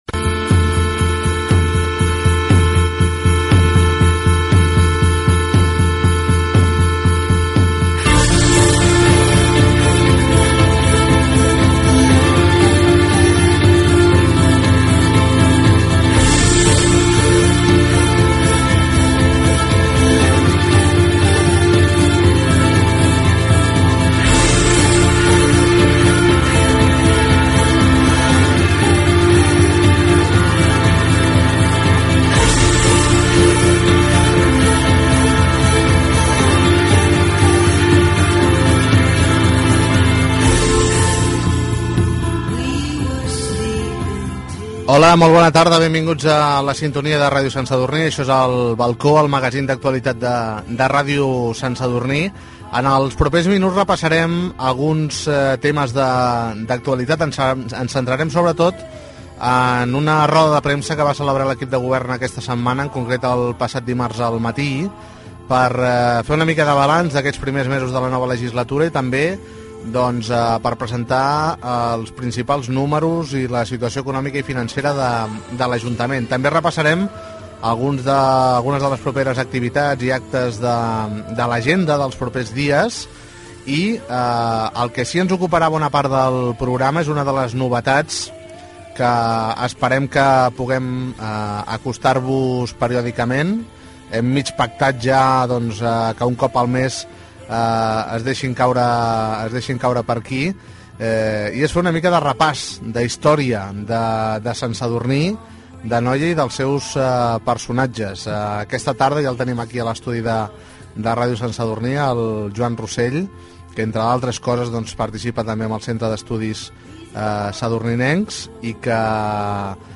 Presentació, sumari de temes, hora, roda de premsa feta al saló de plens de l'Ajuntament sobre l'informe econòmic i financer
Informatiu